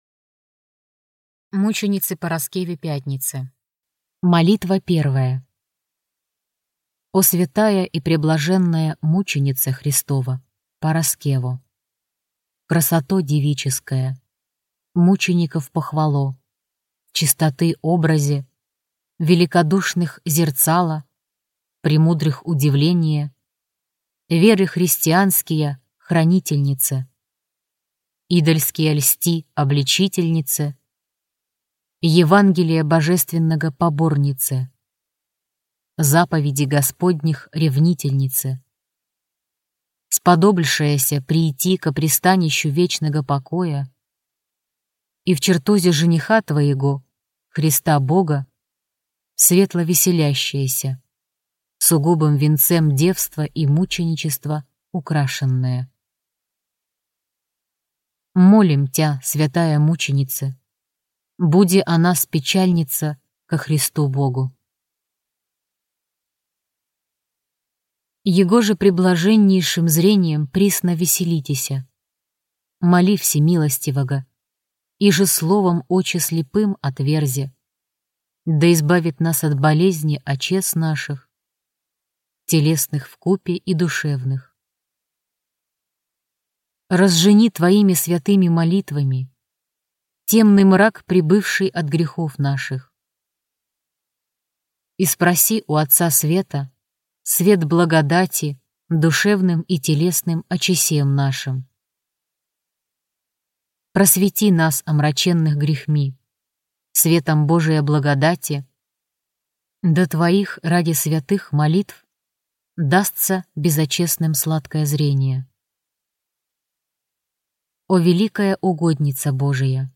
Аудиокнига Молитвы святым | Библиотека аудиокниг